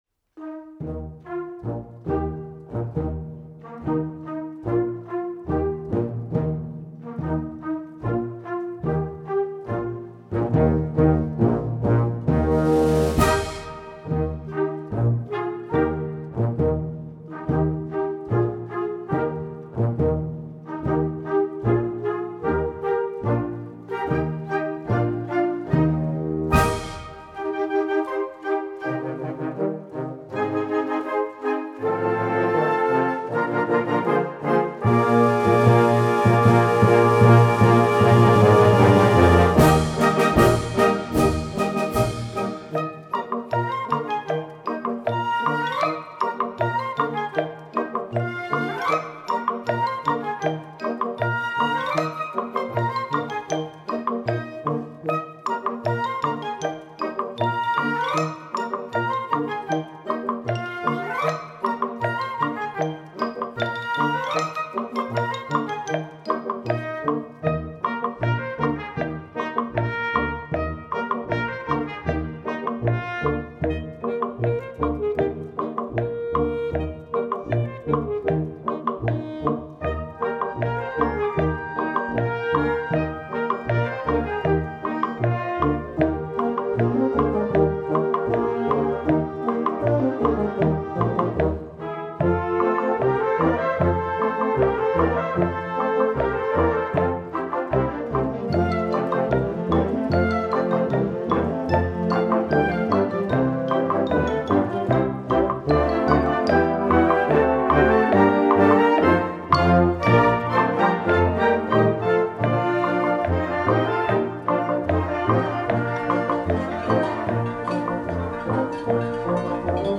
Programmatische Skizze für kleine und große Leute.